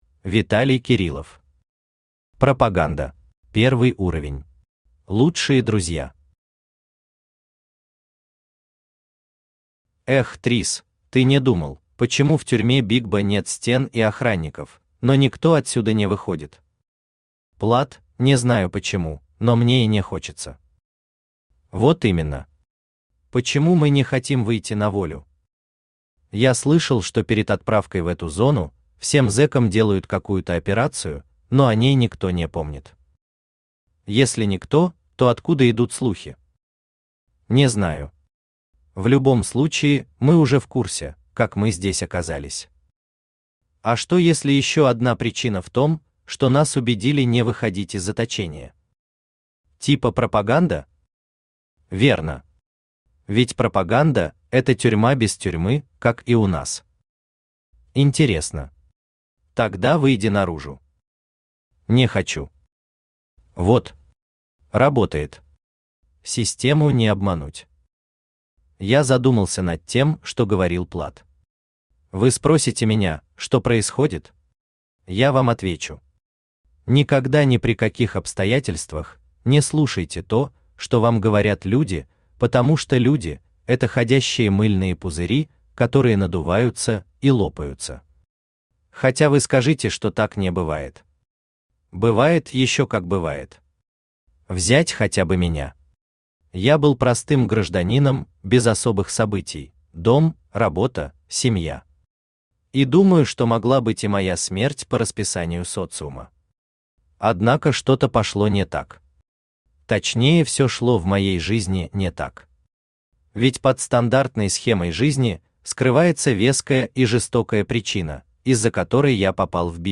Аудиокнига Пропаганда